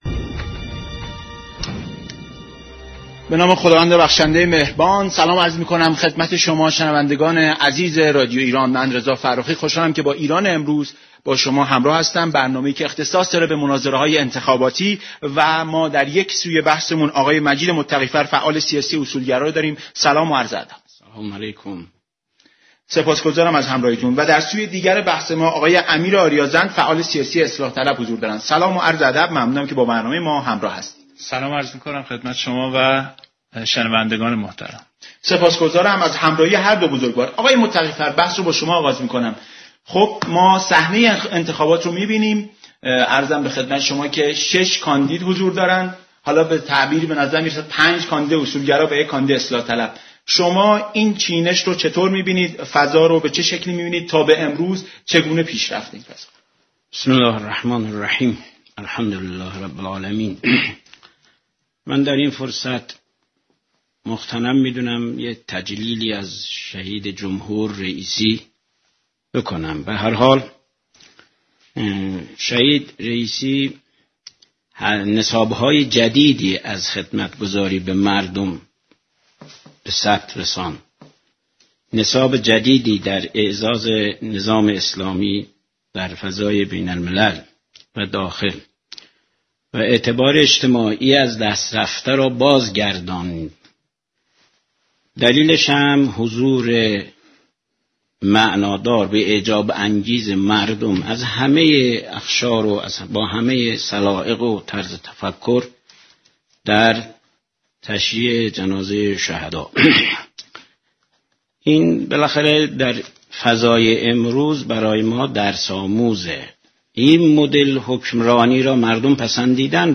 مناظره رادیویی